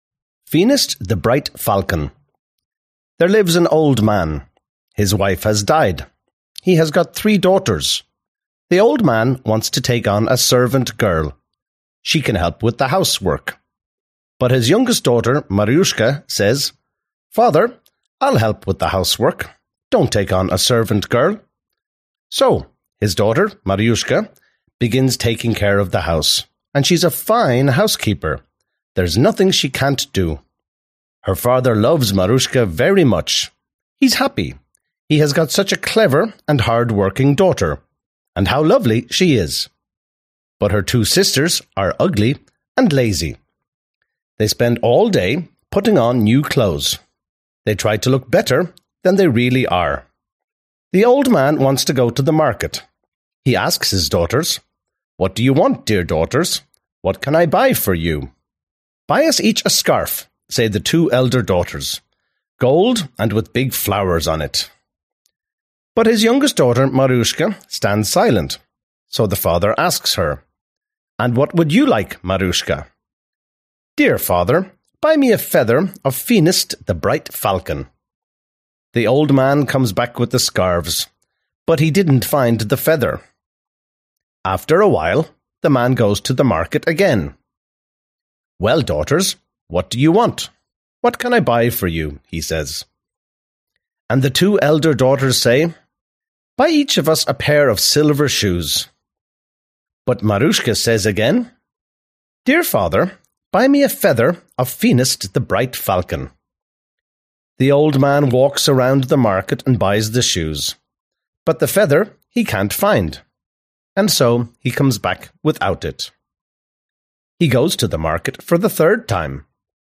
Аудиокнига Самые великие русские сказки на английском языке | Библиотека аудиокниг